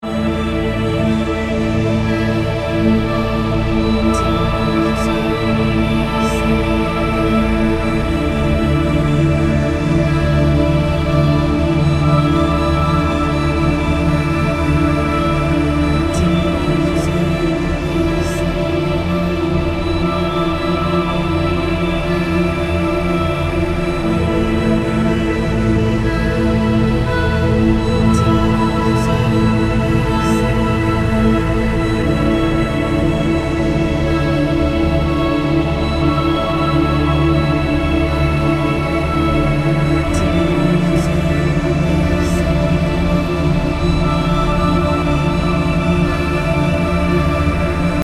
menghadirkan energi lembut tanpa mendominasi